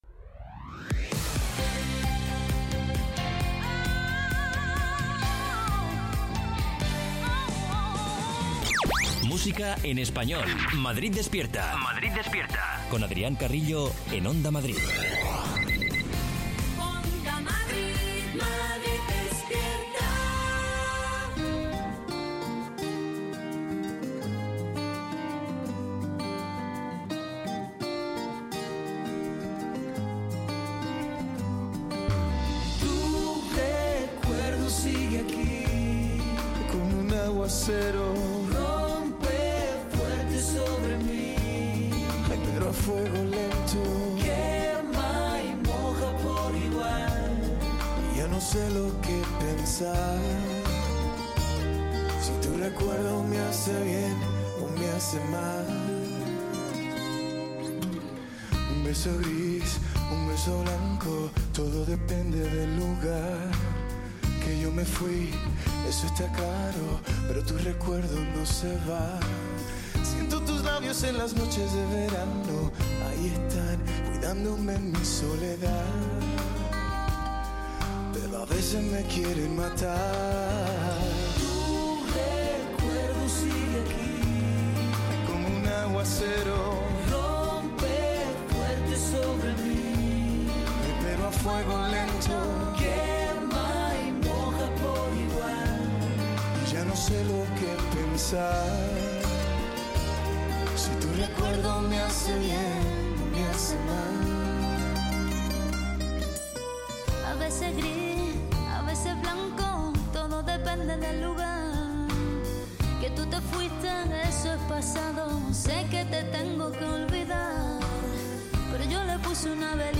Morning show para despertar a los madrileños con la mejor música y la información útil para afrontar el día.